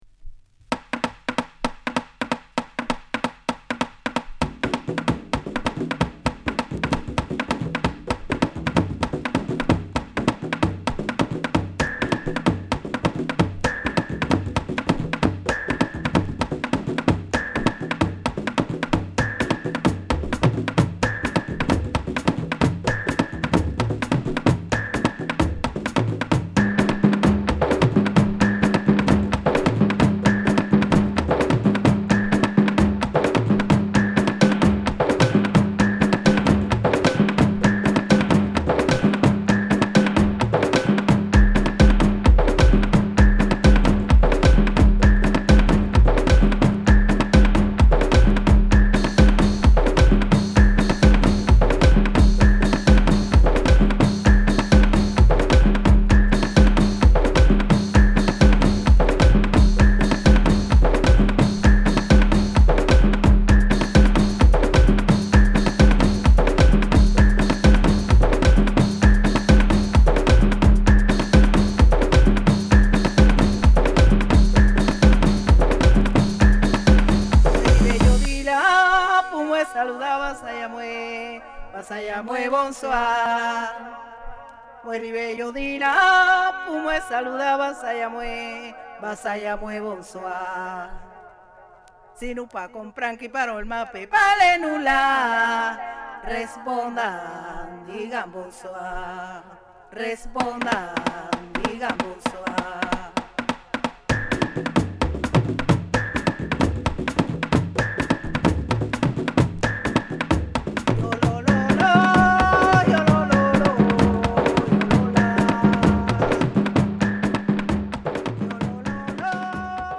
トライバルビートに女性ボーカルが響く